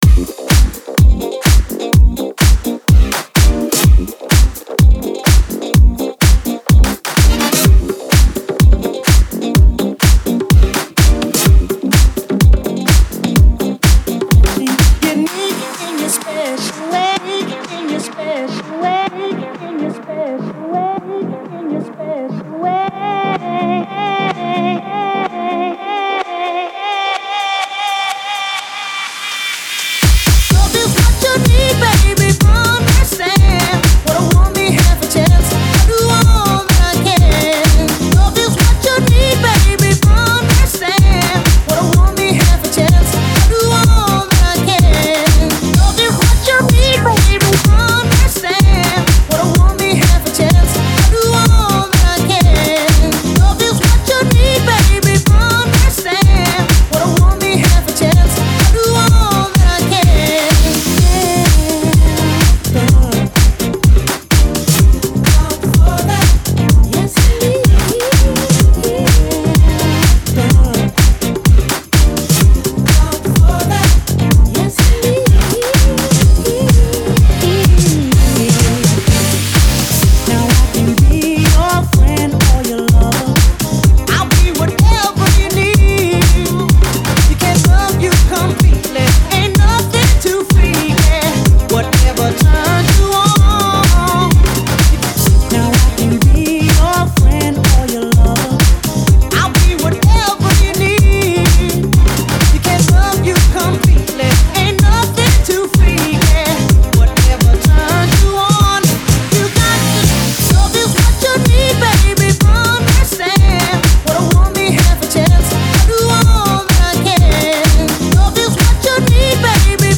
Future Funk